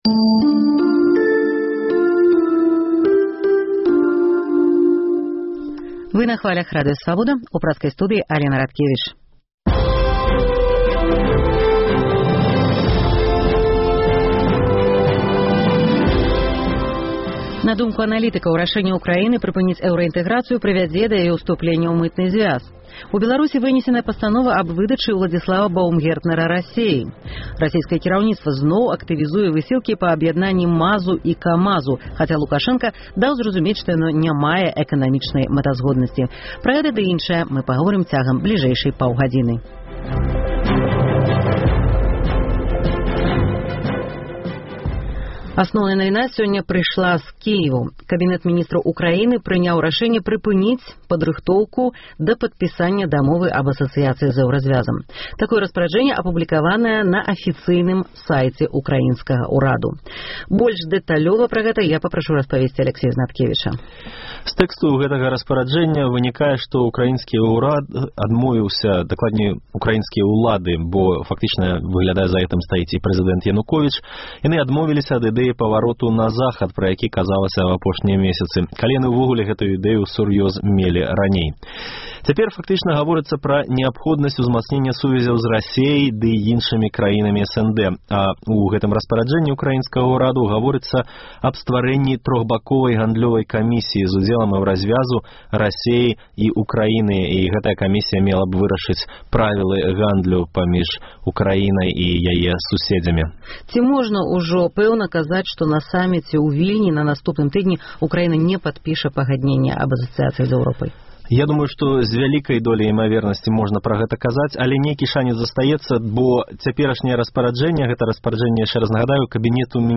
На думку аналітыкаў, рашэньне Ўкраіны прыпыніць эўраінтэграцыю азначае яе вялікі крок у напрамку да Мытнага Зьвязу, а ў пэрспэктыве і да вайскова-палітычнага альянсу. Госьць праграмы – палітычны аналітык з Украіны